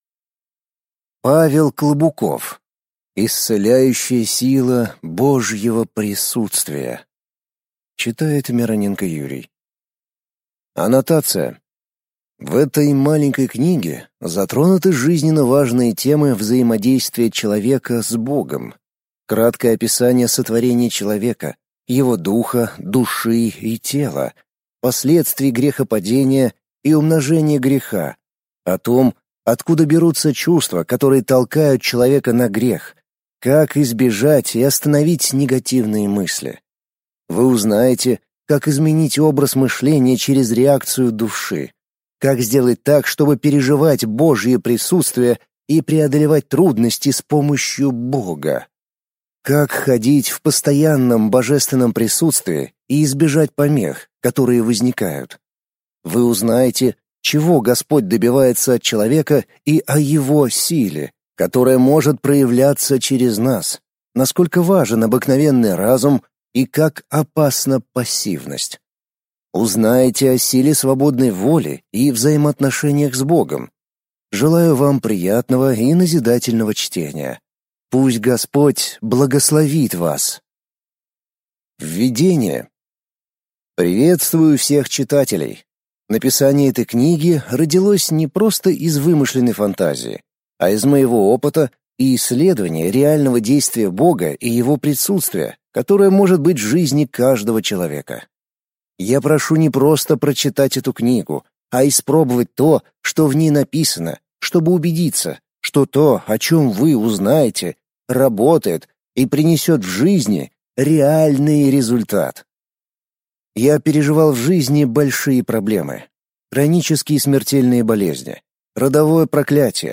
Аудиокнига Исцеляющая сила Божьего присутствия | Библиотека аудиокниг